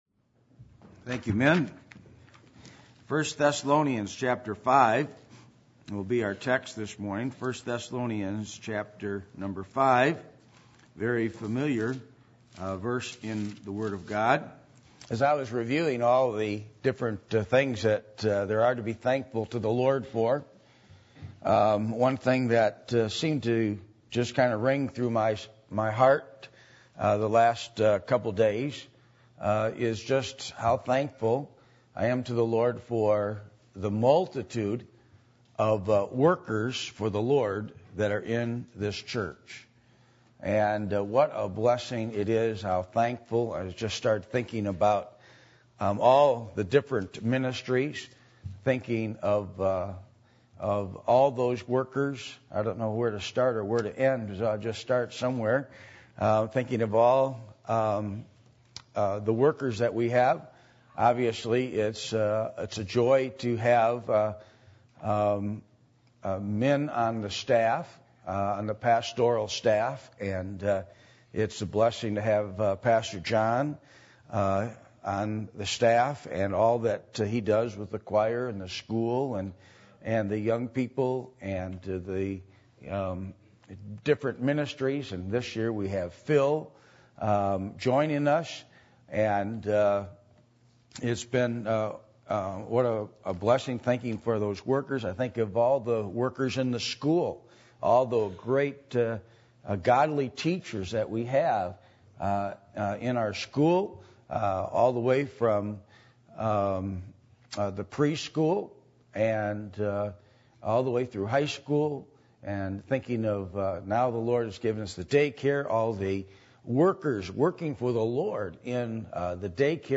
Passage: 1 Thessalonians 5:17-18 Service Type: Thanksgiving